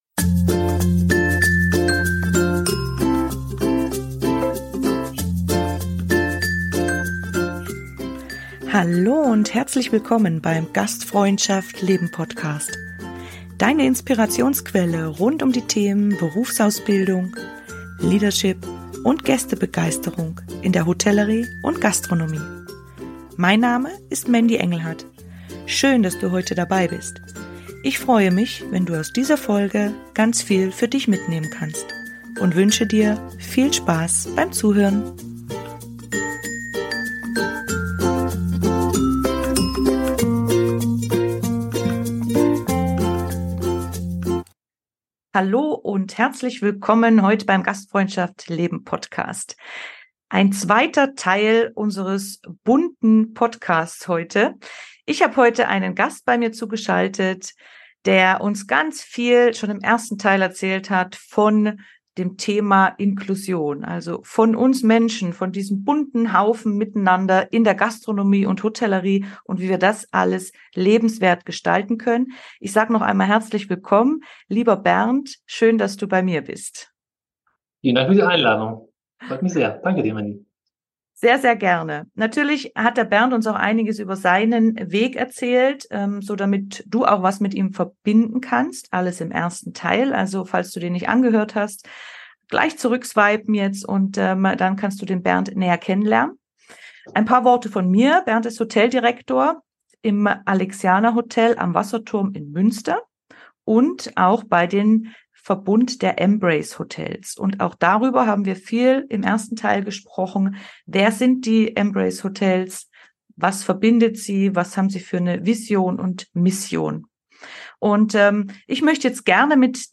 Du darfst dich auf ein wahnsinnig spannendes Interview mit jeder Menge Praxiserfahrungen und Input freuen.